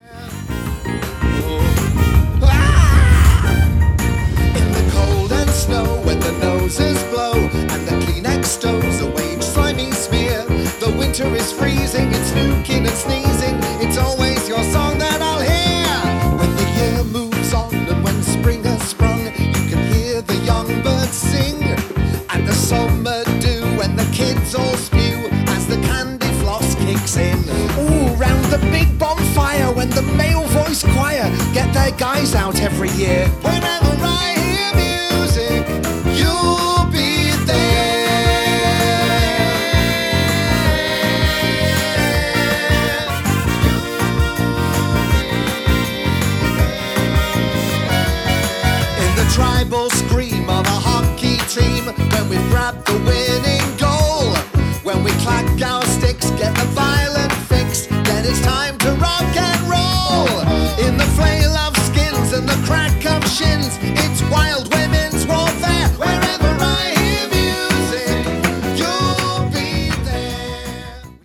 These are performed in similar styles to the finished versions but have different orchestrations and keys.
An up-tempo, full company number citing everyone's Village Hall inspirations